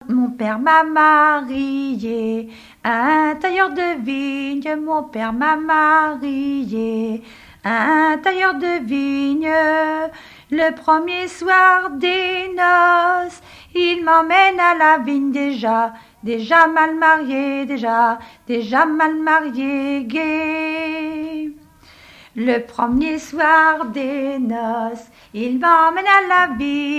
Témoignages sur le mariage et chansons traditionnelles
Pièce musicale inédite